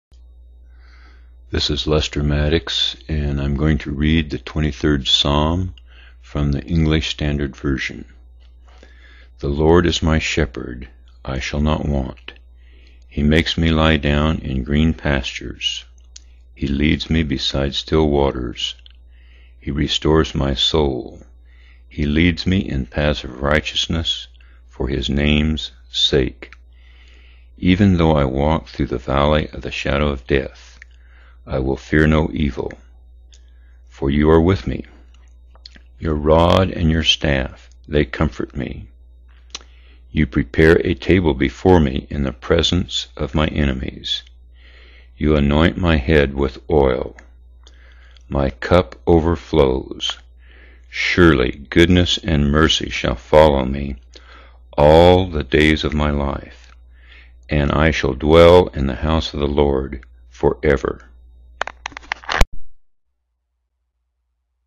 23rd Psalm read by Lester Maddox 🎧 Psalm 23